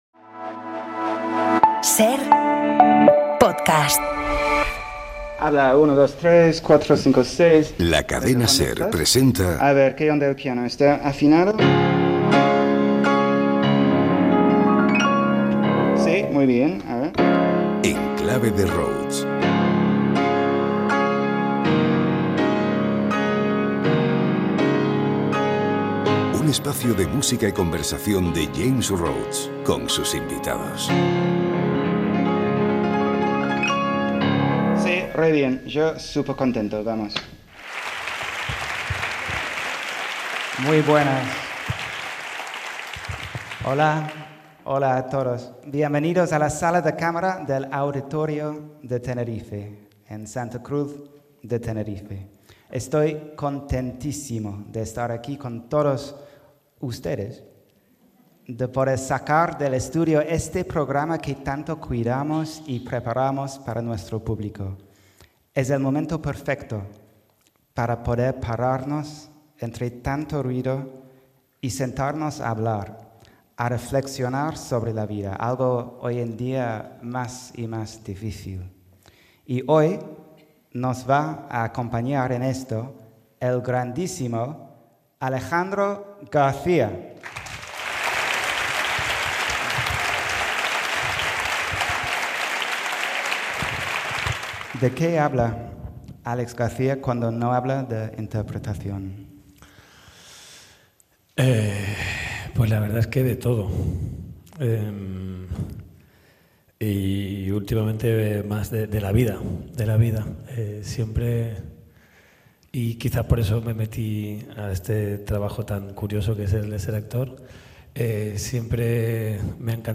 El actor Álex García charla con James Rhodes sobre la vida, el amor, la imagen o el complicado mundo del cine y la televisión, en un momento complicado para el actor, marcado por la pérdida de su expareja, la actriz Verónica Echegui. En una íntima conversación en el Auditorio de Tenerife, además de ambos artistas, la música, en manos de Rhodes, es protagonista.